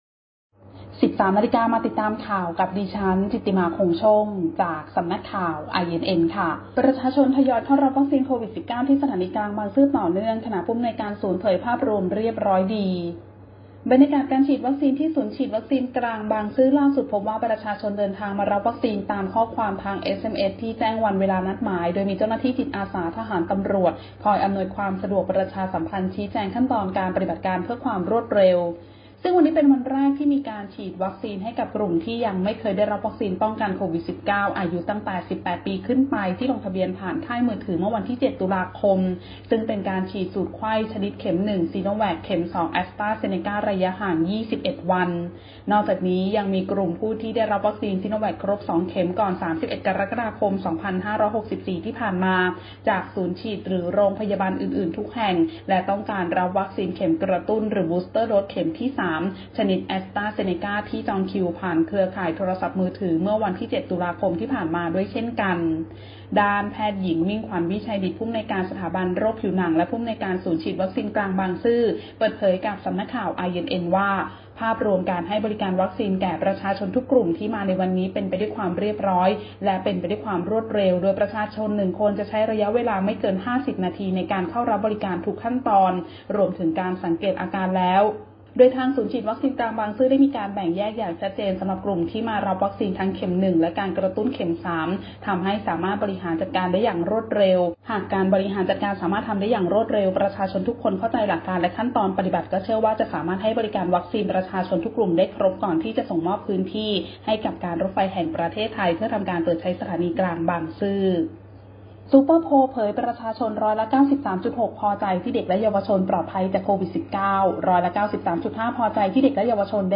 คลิปข่าวต้นชั่วโมง
ข่าวต้นชั่วโมง 13.00 น.